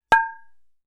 Metal_31.wav